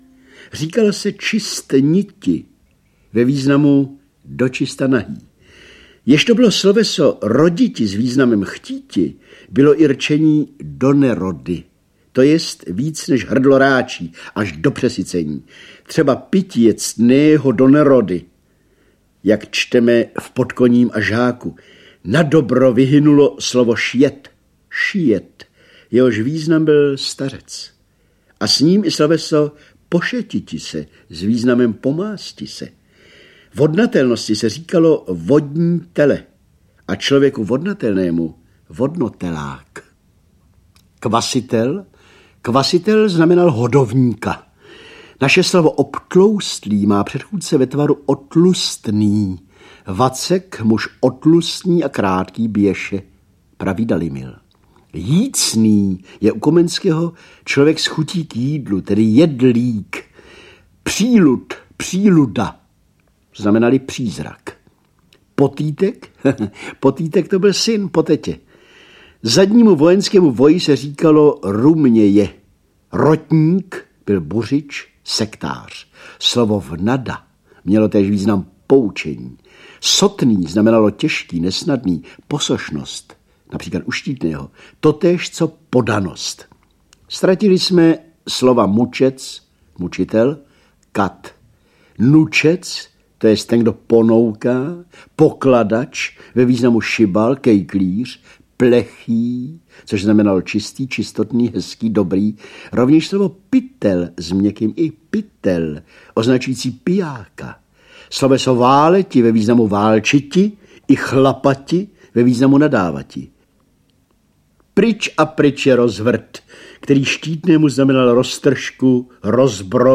Audio kniha
Ukázka z knihy
Chrám i tvrz - audiokniha obsahuje rozhlasovou nahrávku z roku 1981.
Čte Miroslav Horníček.